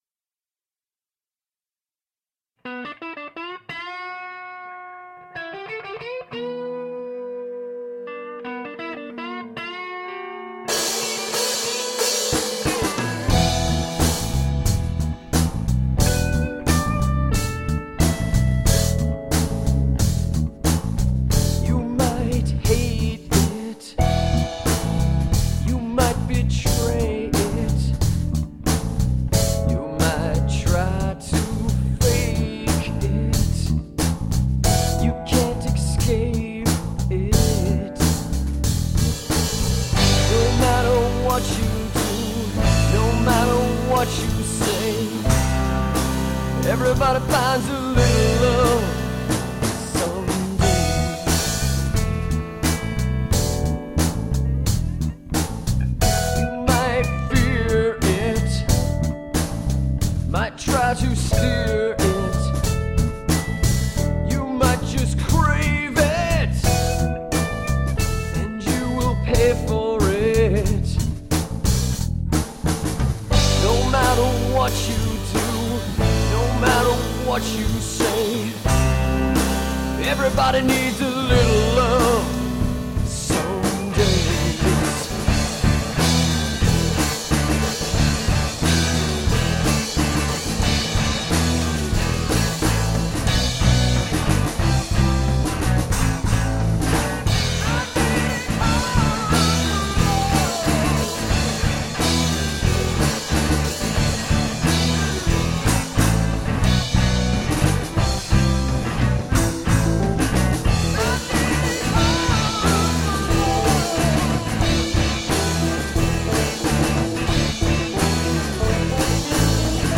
with Strings from